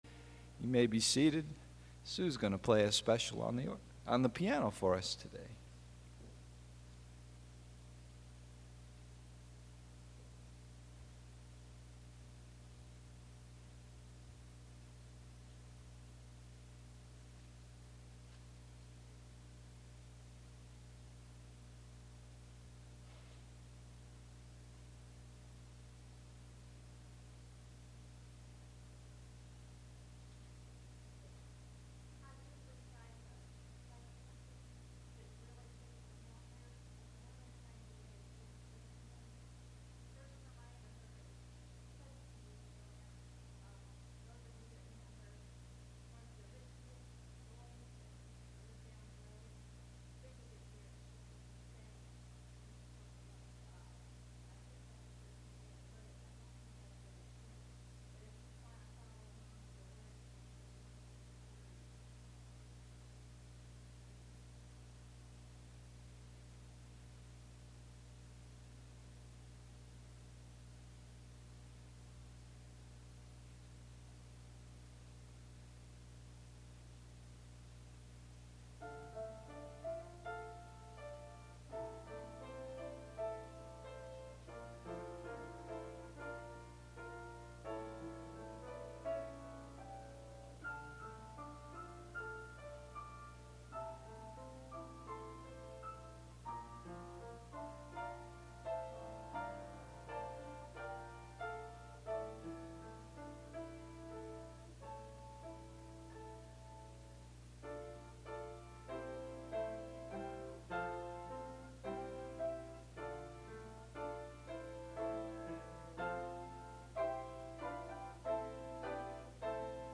Chasing Bubbles AM Service